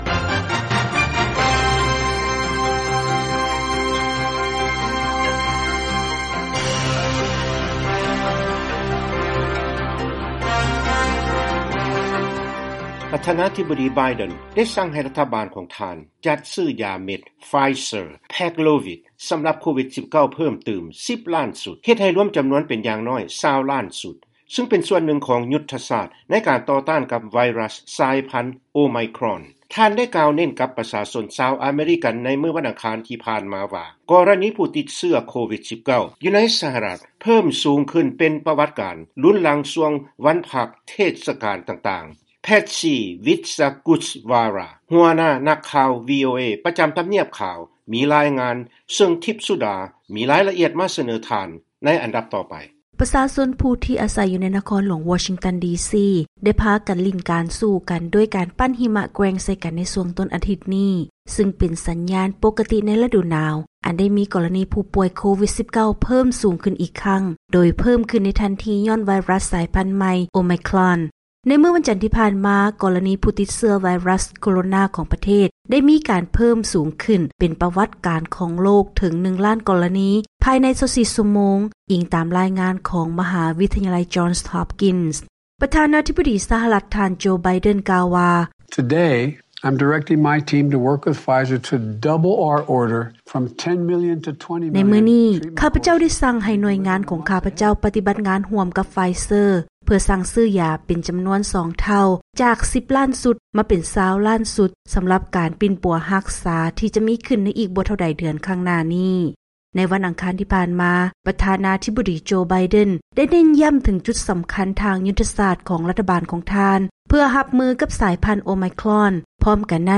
ລາຍງານກ່ຽວກັບ ທ່ານ ໄບເດັນ ມີຄໍາສັ່ງໃຫ້ຊື້ຢາເມັດສໍາລັບໂຄວິດເປັນສອງເທົ່າ ເພື່ອຕໍ່ຕ້ານກັບໄວຣັສໂອໄມຄຣອນ